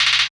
perc - uzi.wav